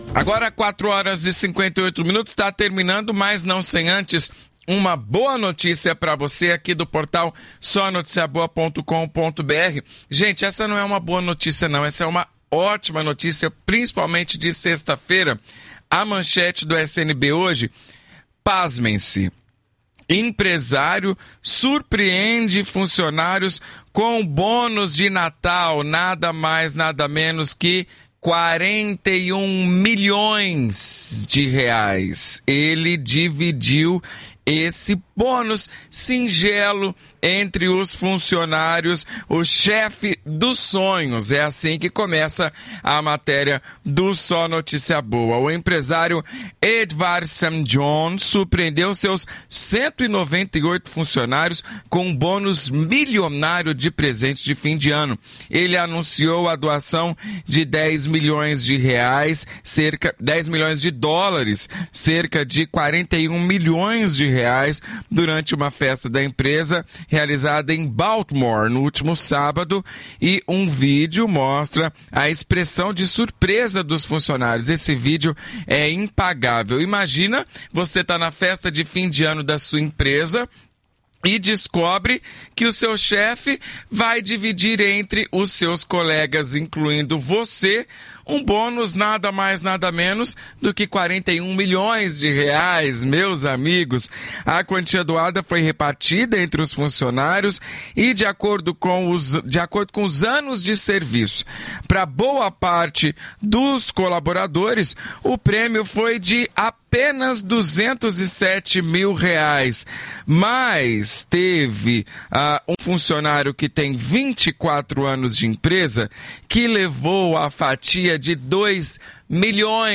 O quadro SNB na CBN vai ao ar de segunda a sexta às 16:55 na rádio CBN Grandes Lagos.